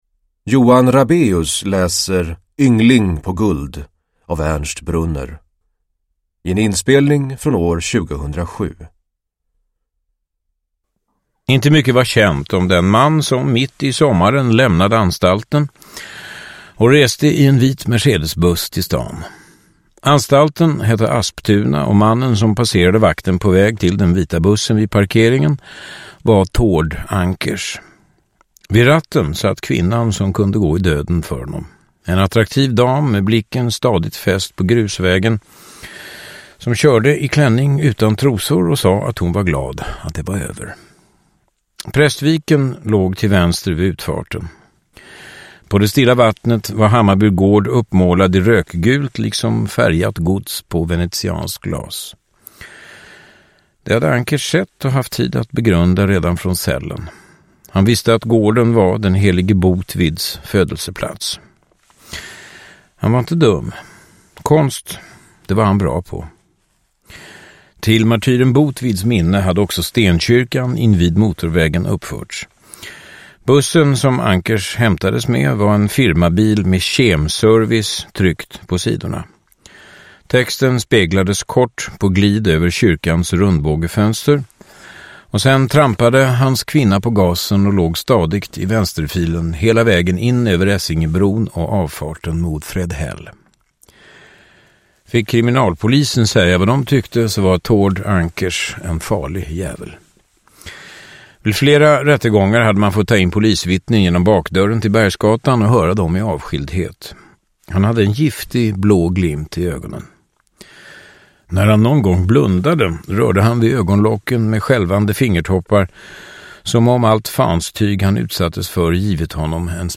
Yngling på guld : Roman om ett brott – Ljudbok
Uppläsare: Johan Rabaeus